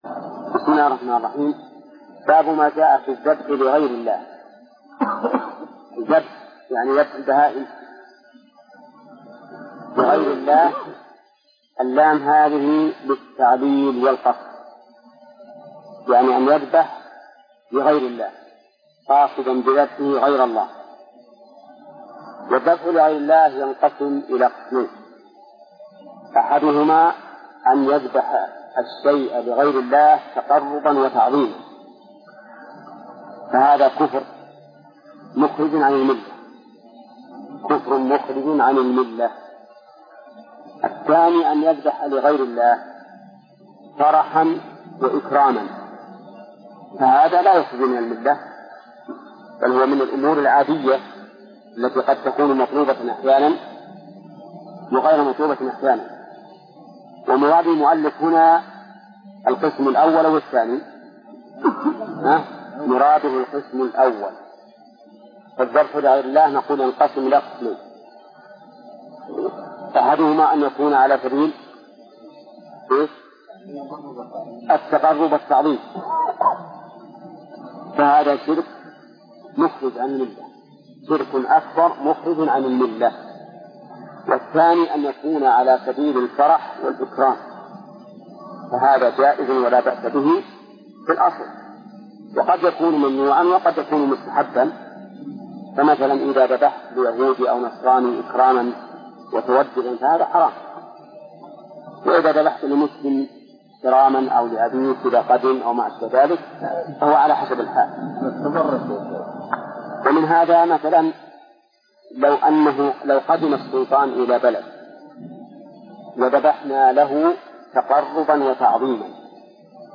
درس (11): من صفحة: (214)، قوله: (باب ما جاء في الذبح لغير الله).، إلى صفحة: (237)، قوله: (أقسام النذر).